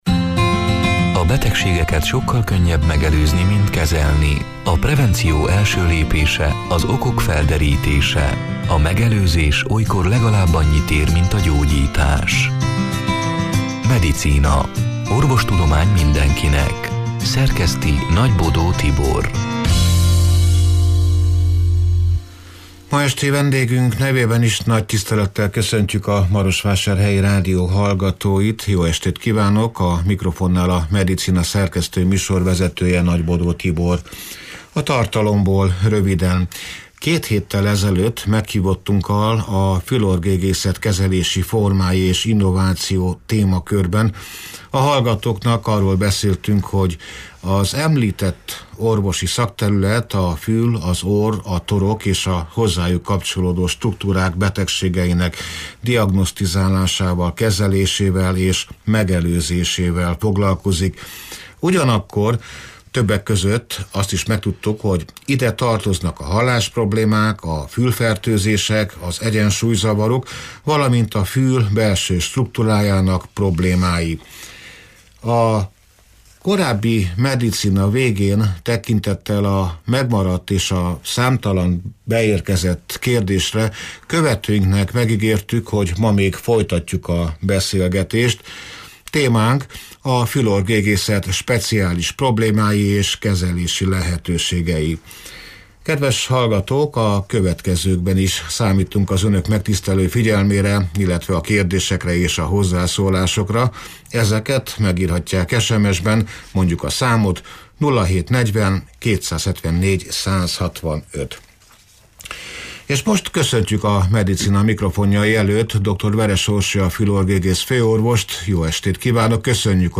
A Marosvásárhelyi Rádió Medicina (2025. március 19-én, szerdán este nyolc órától élőben) c. műsorának tartalmából: Két héttel ezelőtt meghívottunkkal A fül-orr-gégészet kezelési formái és innováció témakörben a hallgatóknak arról beszéltünk, hogy az említett orvosi szakterület a fül, az orr, a